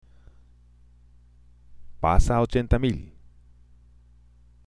（パサ　ア　オチェンタ　ミル！）